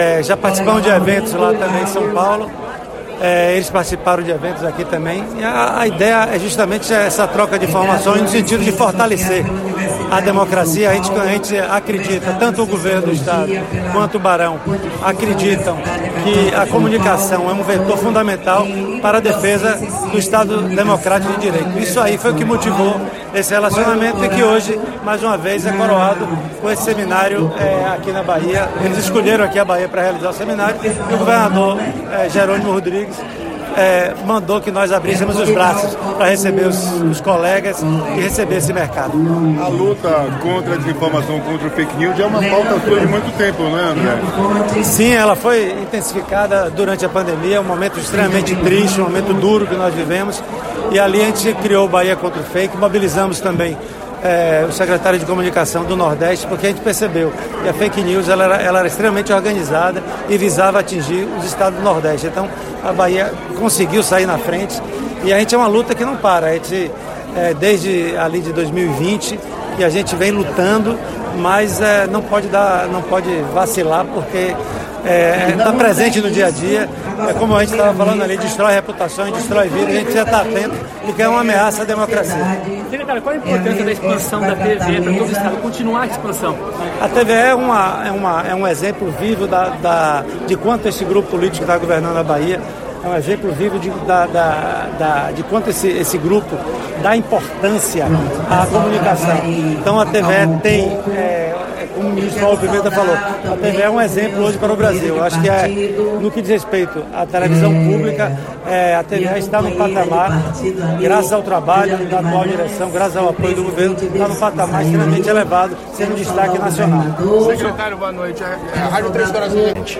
Organizado pelo Centro de Estudos Barão de Itararé, o evento foi aberto pelo governador Jerônimo Rodrigues que também assinou acordo de cooperação entre que expande o sinal da TV Educativa do Estado (TVE) e da TV Educa Bahia, garantindo o acesso às emissoras públicas nos Territórios de Identidade baianos.